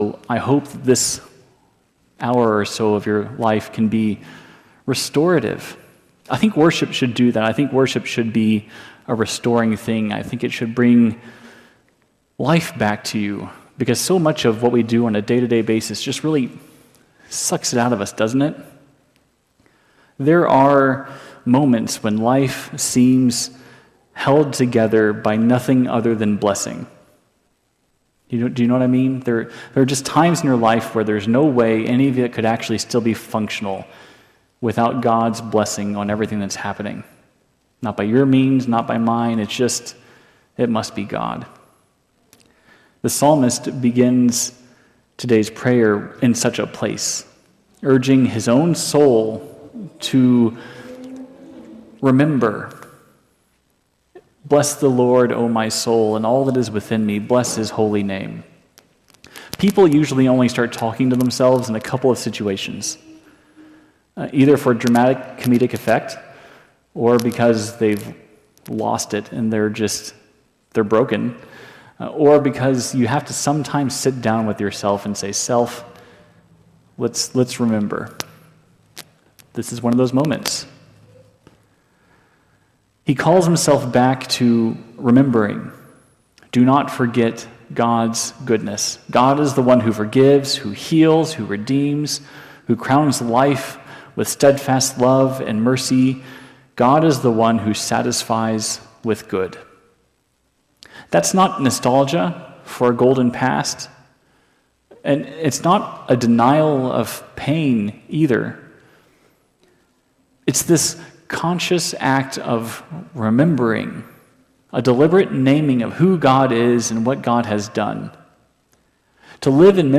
The psalm invites us into heartfelt praise for God’s mercy, forgiveness, and healing. It reorients the community toward gratitude and a transformed life marked by compassion. This sermon calls believers to embody God’s restorative love and live as agents of healing.